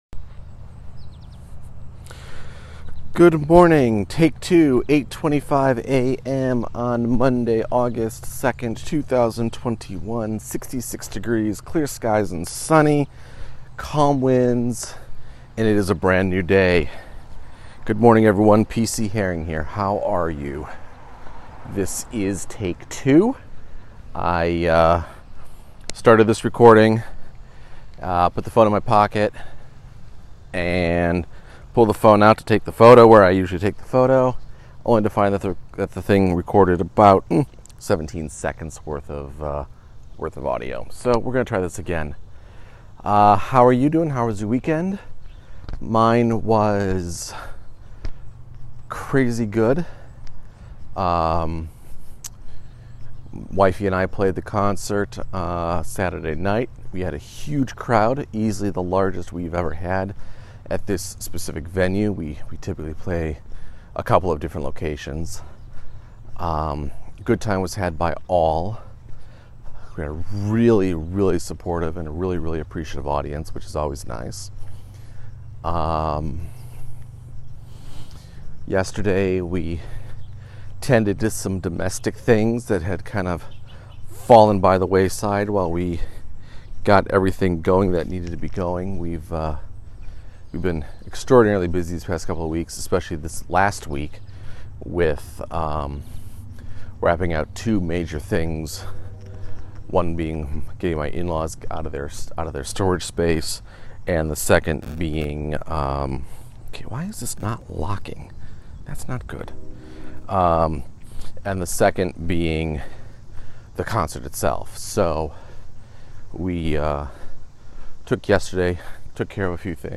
A shorter walk today, in which I recap the weekend, provide a brief writing update and get my head in place to get back to a regular routine